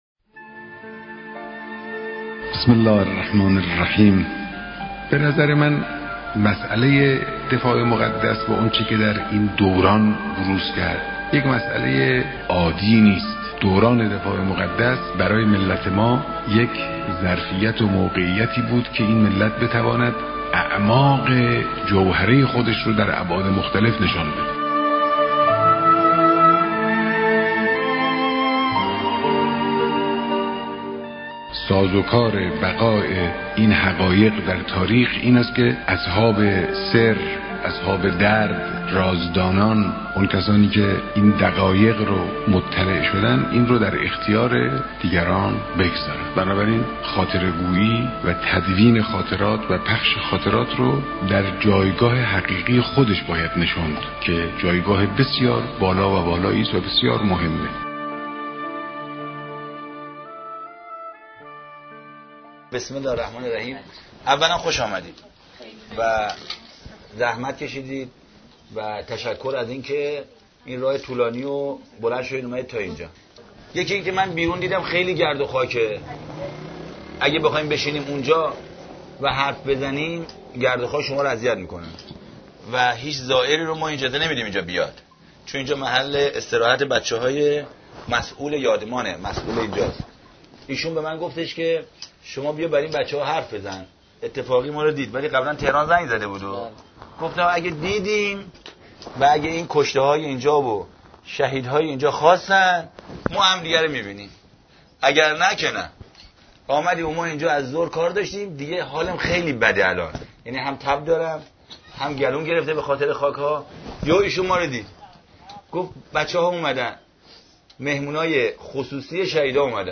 روایتگری
در جمع دانشجویان خارجی در یادمان شلمچه